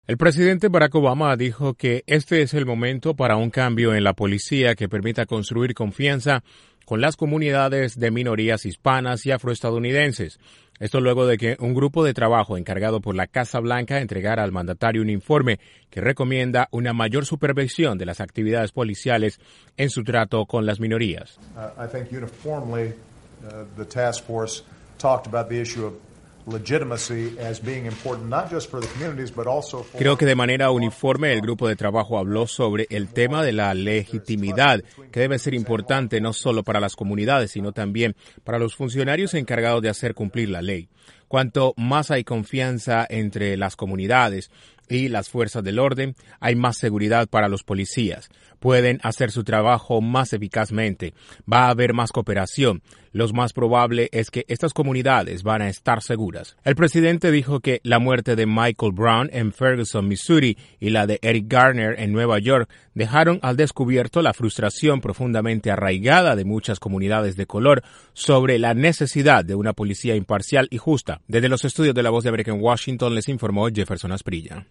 El presidente Barack Obama dijo que las muertes de personas de minorías hispanas y afroestadounidenses a manos de policías demuestra que las fuerzas del orden deben modificar su manera de actuar. Desde la Voz de América en Washington DC informa